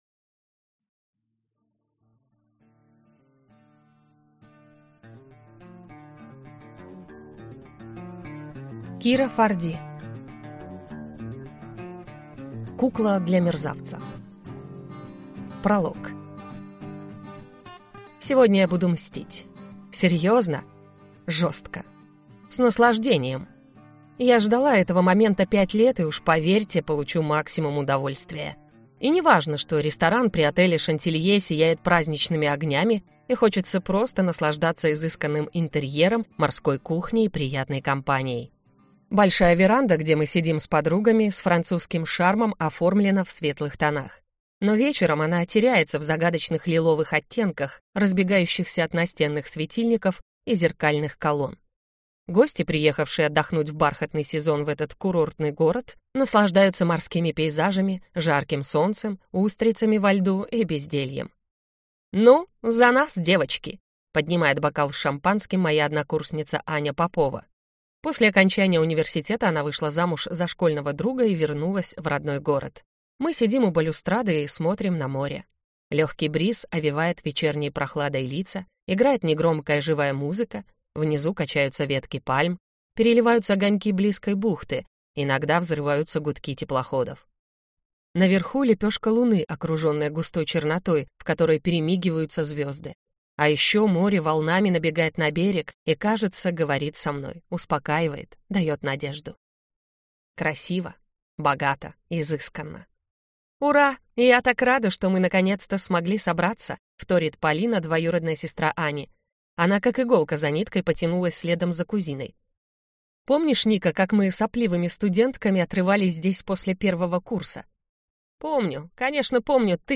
Аудиокнига Кукла для мерзавца | Библиотека аудиокниг
Прослушать и бесплатно скачать фрагмент аудиокниги